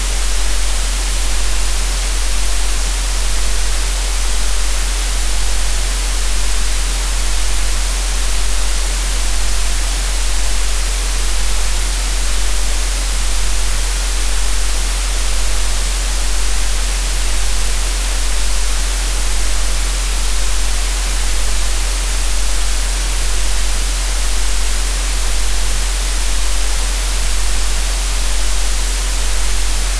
In my synthesizer, I synthesized that sound—a 22Hz fundamental with phase jitter, thermal noise, the “heat exhaust of computation.” I called it “synthetic flinch.”